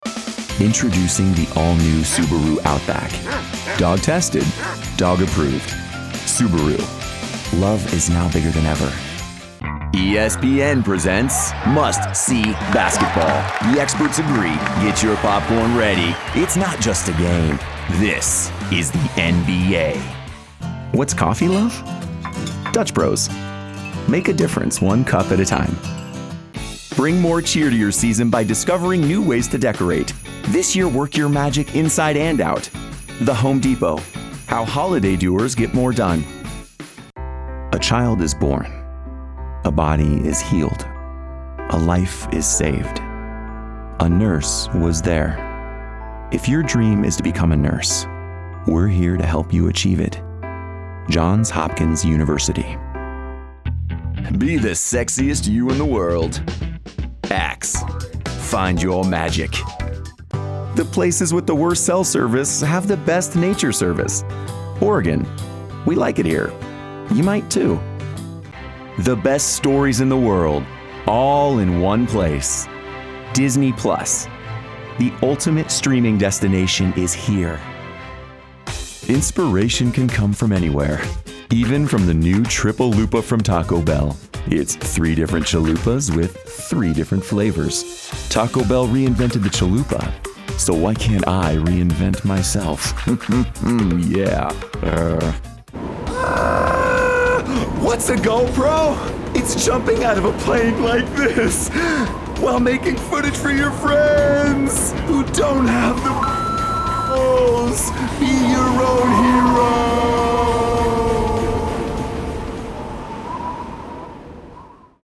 All-American, conversational, non-announcer announcer, that's me! The guy next door who speaks to you with warmth, caring, a sense of humor and a touch of charm. Trustworthy yet casual.